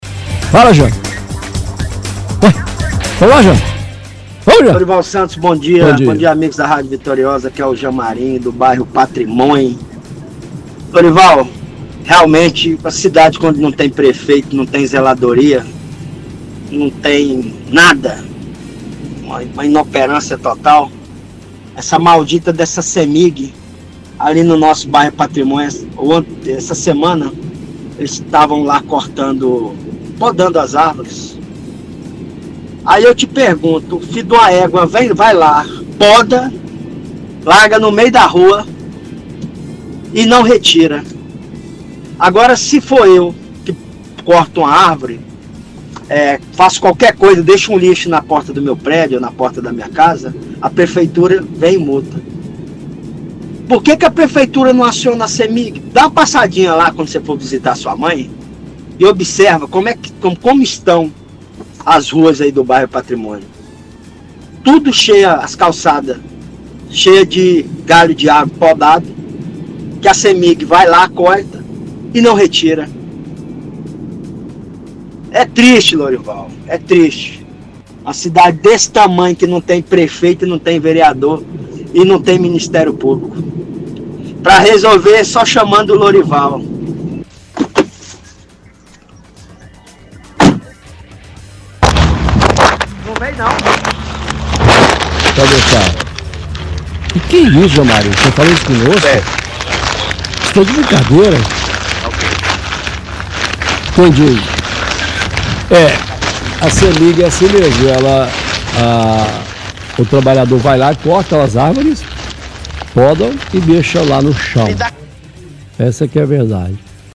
Ouvinte reclama que a cidade não tem prefeito e relata caso de que a Cemig podou árvore, mas não retirou o descarte.